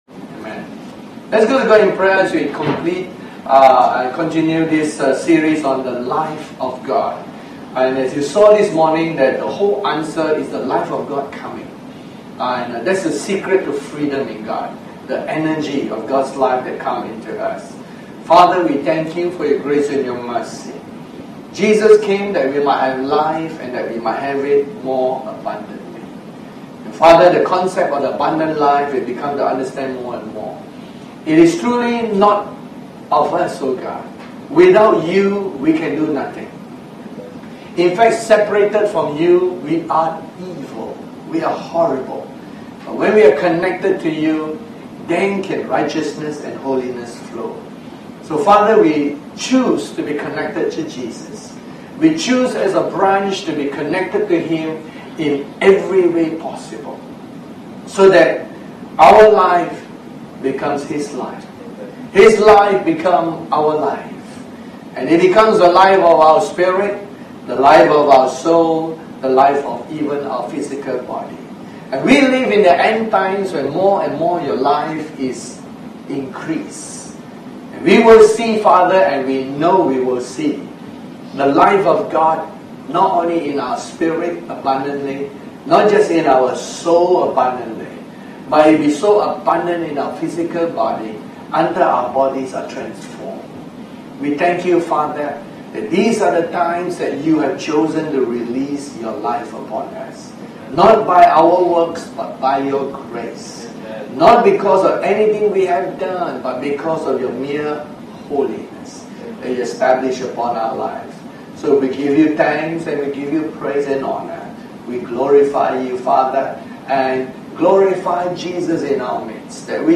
Series: The Life of God Tagged with Sunday Service